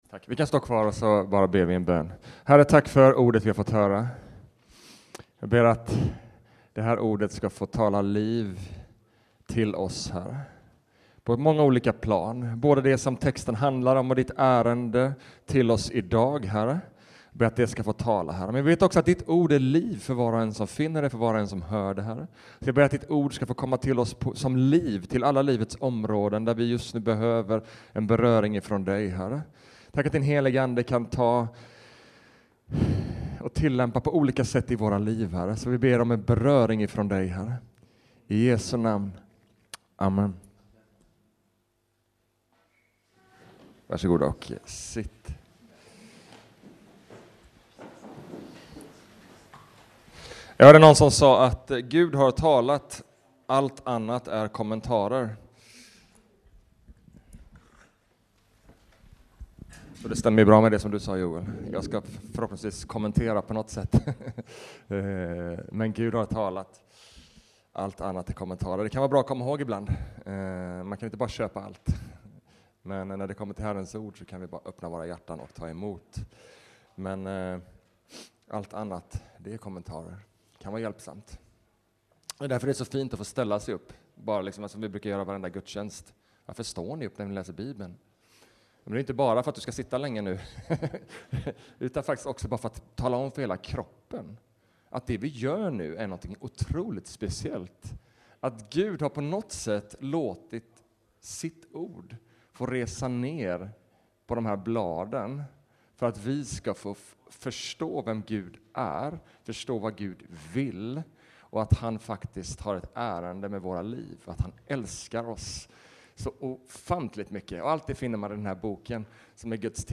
Sermon talare